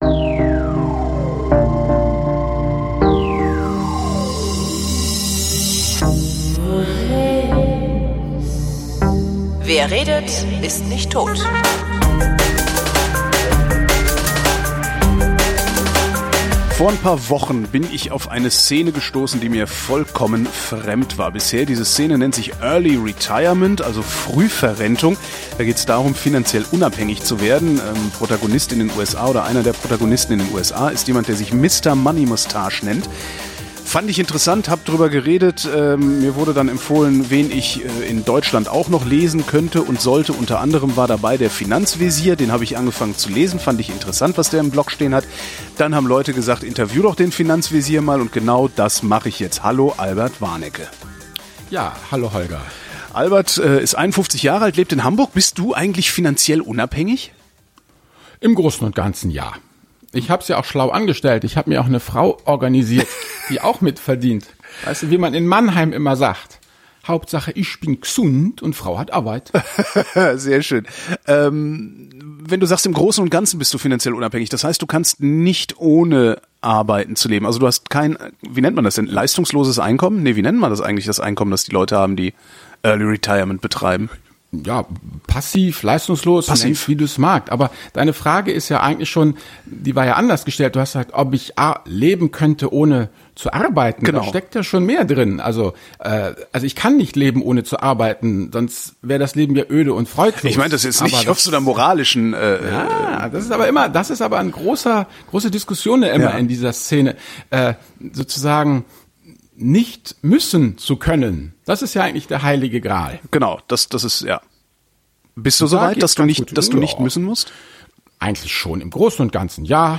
Er lebt in Hamburg und publiziert über Möglichkeiten, finanziell unabhängig zu werden, also nicht mehr müssen zu können. Ich hatte Gelegenheit, ein wenig mit ihm zu plauern.